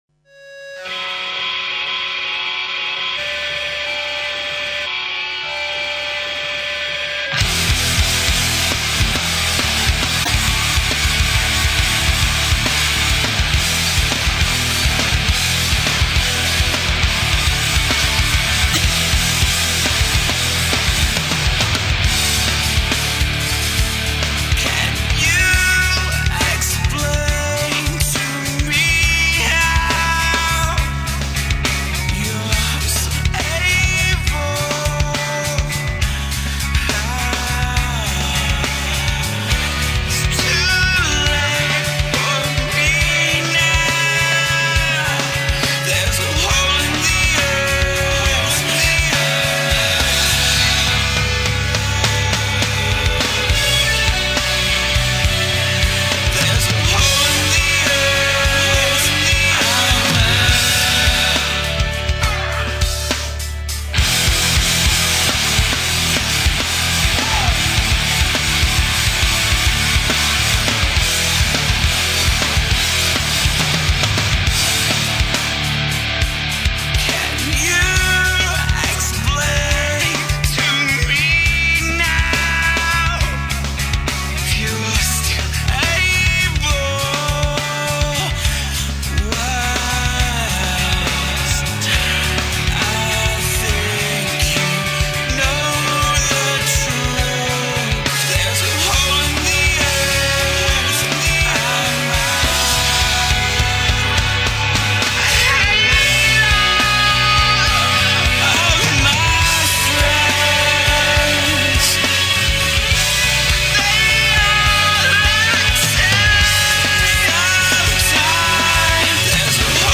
Genre: Nu Metal